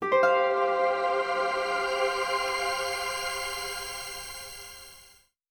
Added connect sound
connect.wav